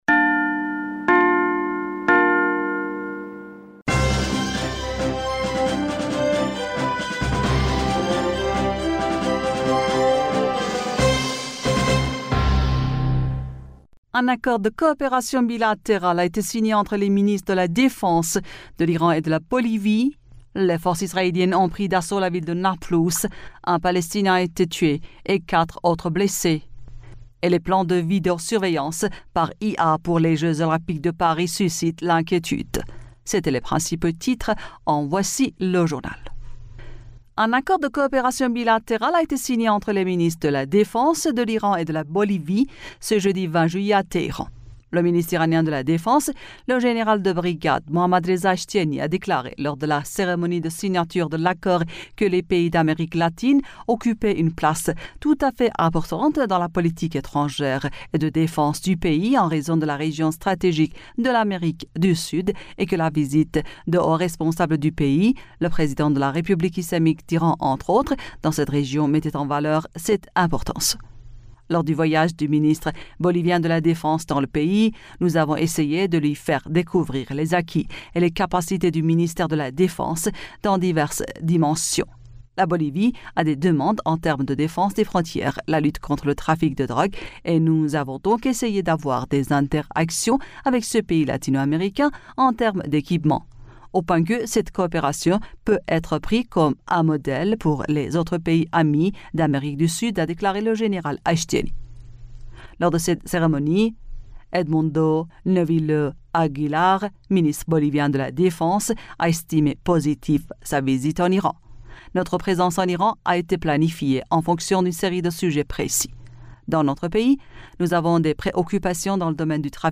Bulletin d'information du 20 Juillet 2023